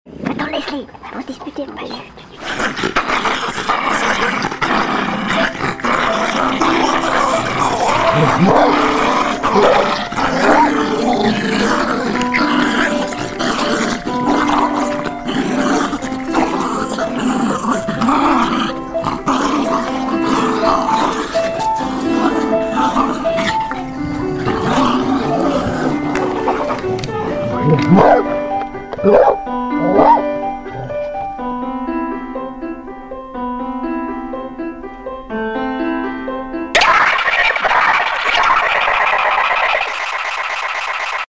JINGLES